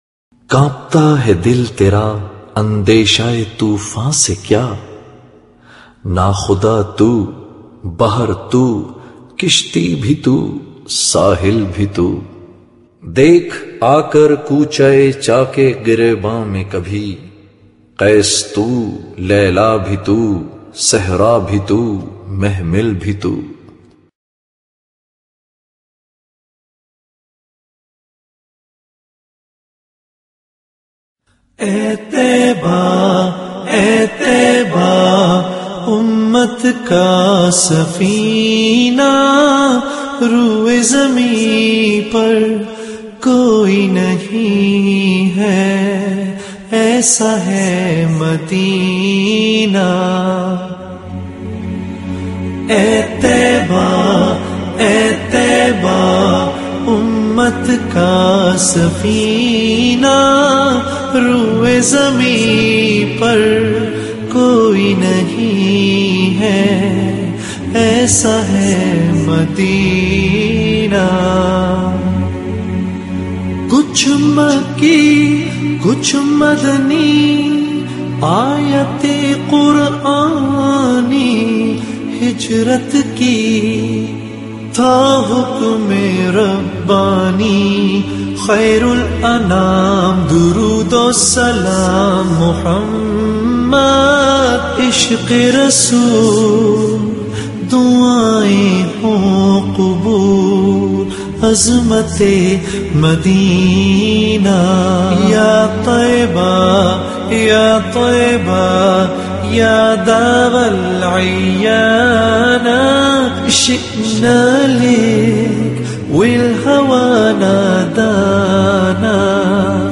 انشودة
لكن هالمره بالهندي وبصراحه عجيبه وفيها شوي عربي
رووووعه قمة الهدووووووء